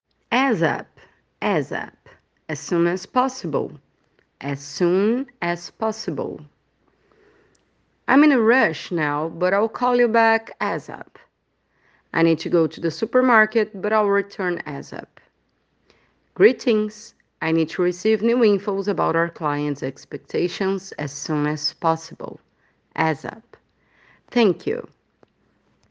Attention to pronunciation 🗣👂🏻: